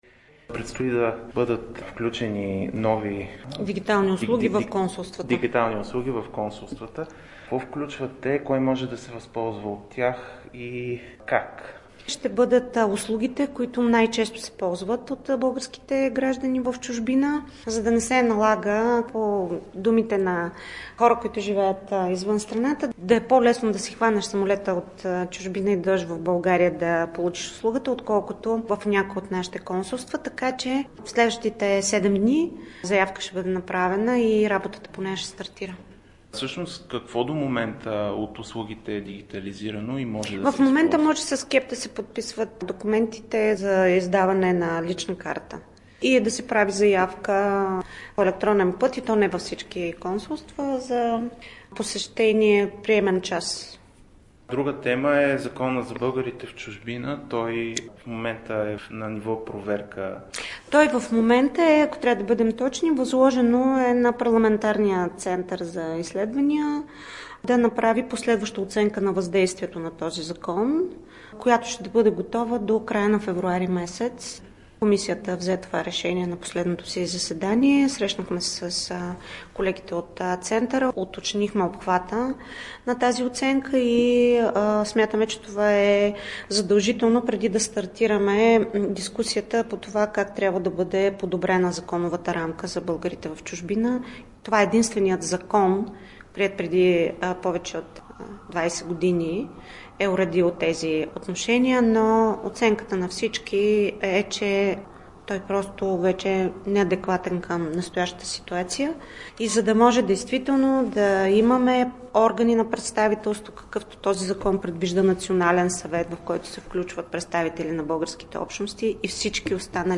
В специално интервю за Радио България Антоанета Цонева отговори на важни въпроси – ще бъде ли променен Законът за българите в чужбина, как да се оптимизира работата на Агенцията за българите в чужбина, кога електронните услуги реално ще могат да се ползват от сънародниците ни зад граница…